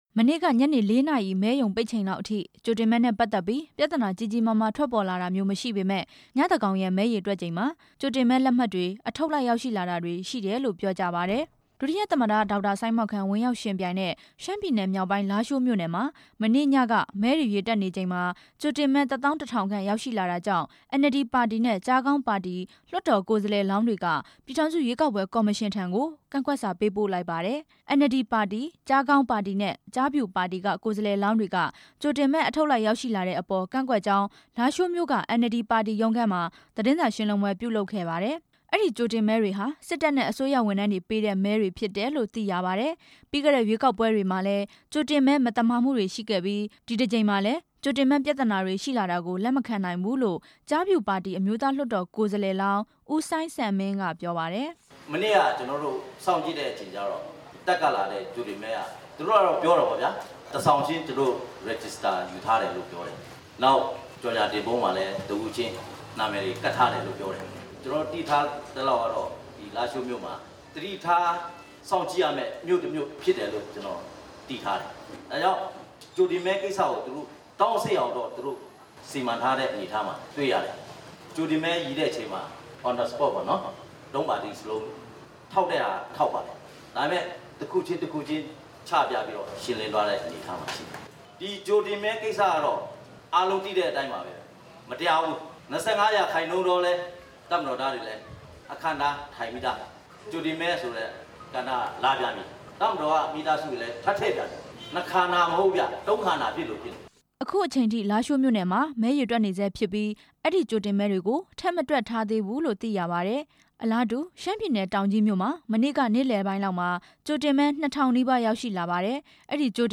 ကြိုတင်မဲလက်မှတ်တွေ အထုပ်လိုက် ရောက်ရှိလာတဲ့ အကြောင်း တင်ပြချက်